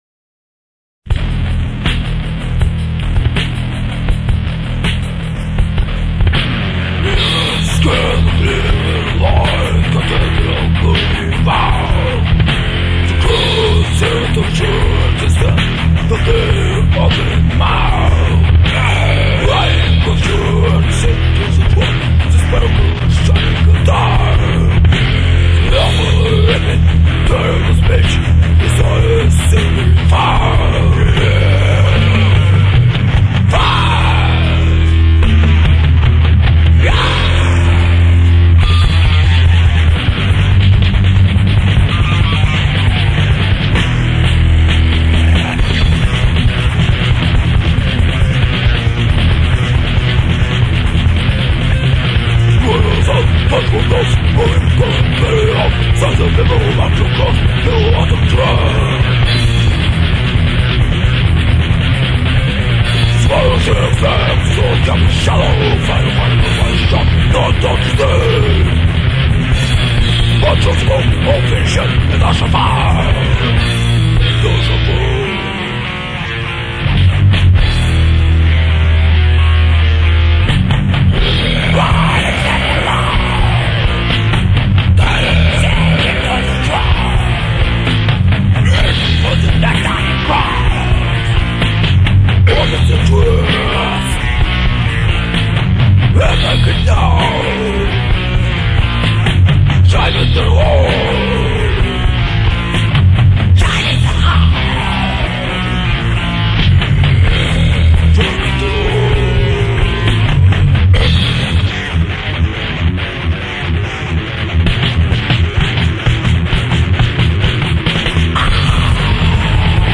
...всё это Rock"n"Roll......и не только...
Демоальбом - г. Актау